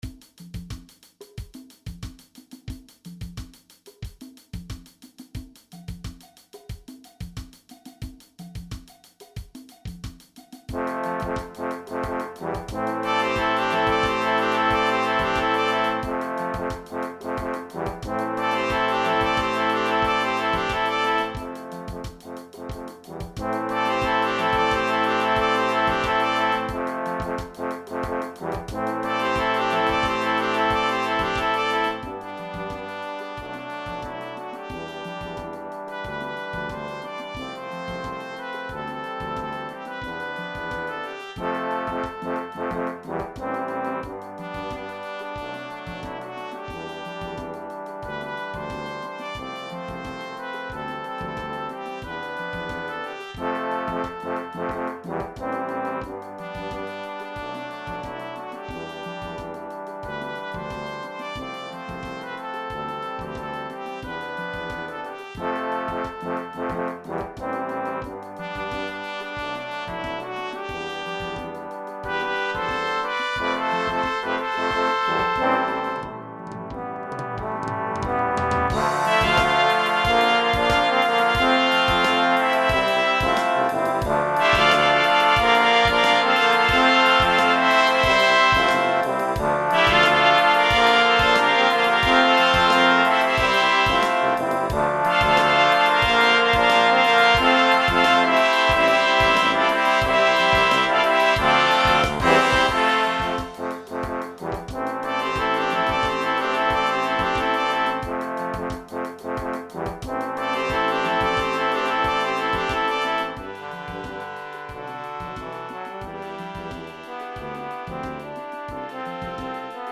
Trumpet 1 in Bb
Trombone 1
Bass Trombone
Tuba
Drum Set – Optional
Percussion(Congas & Cowbell) – Optional